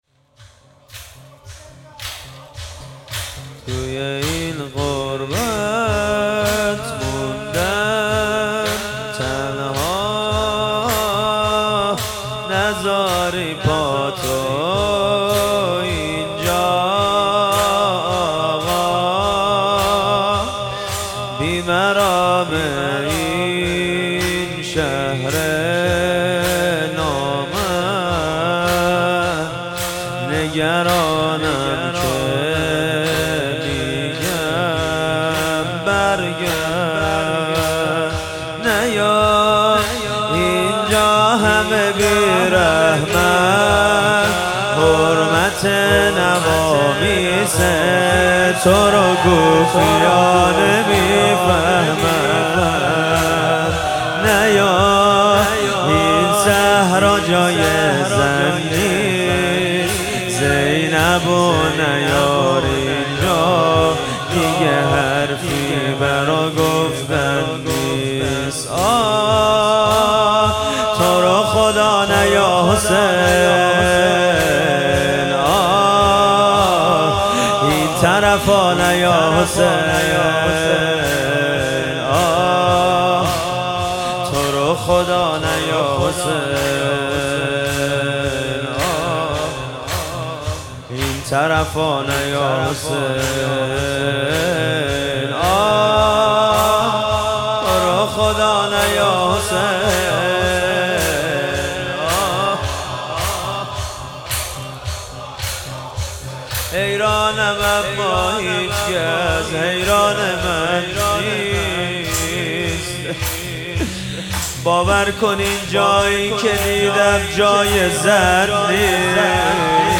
محرم 1401 - شب اول - زمینه - تو رو خدا نیا حسین
محرم 1401